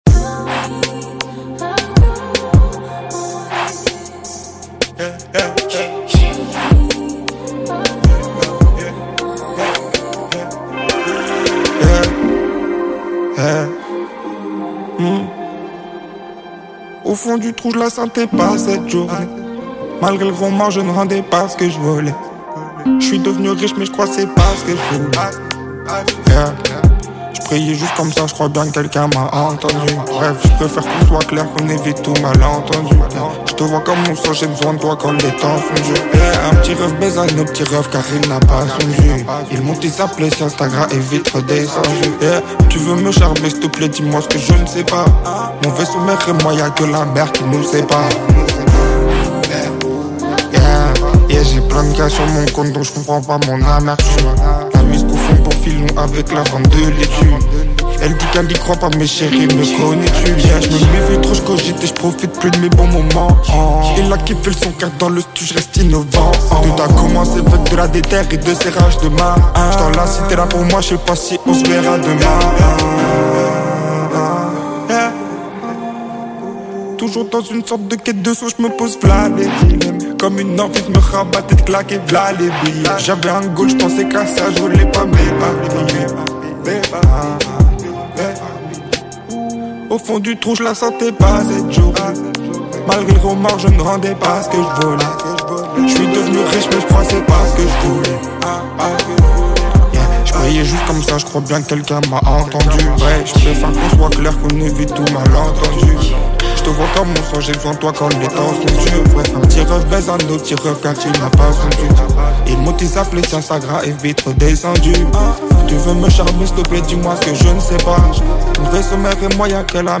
french rap, french r&b, pop urbaine Télécharger